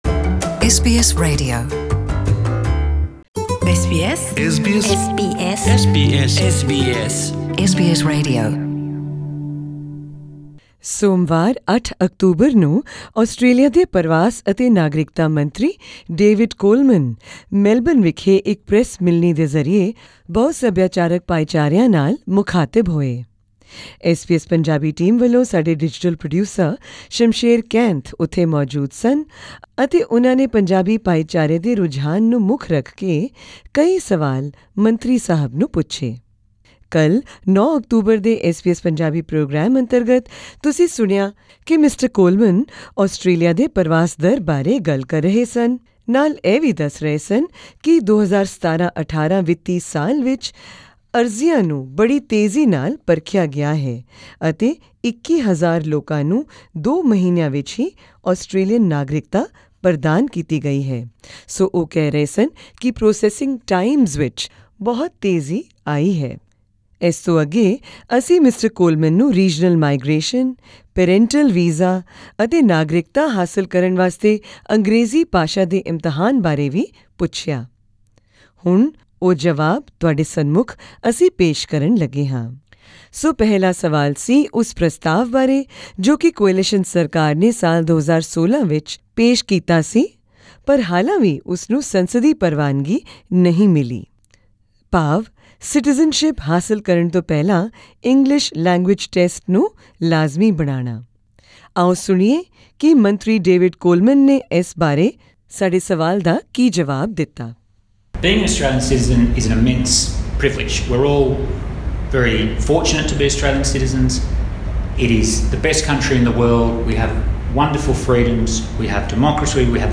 Speaking to SBS Punjabi at a media conference in Melbourne earlier this week, the newly instated Minister for Immigration, Citizenship and Multicultural Affairs David Coleman said, 'there are certain values that people must conform to before becoming an Australian citizen.